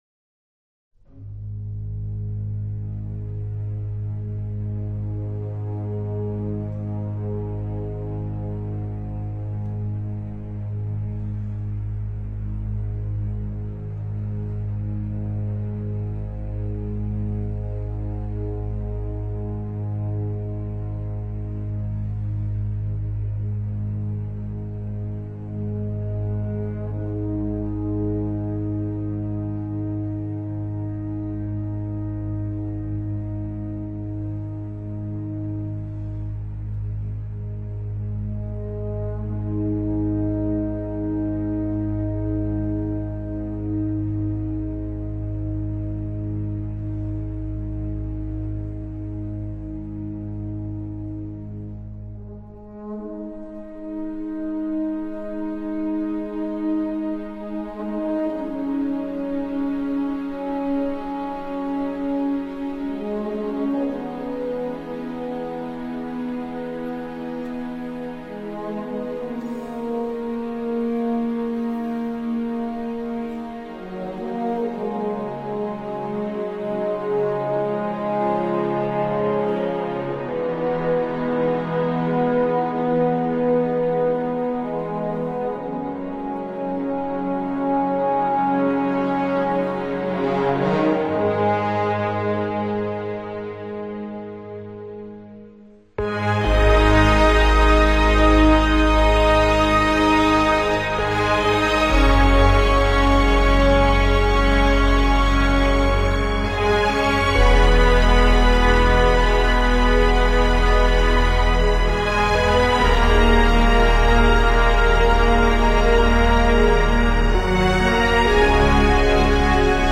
Electronic, Soundtrack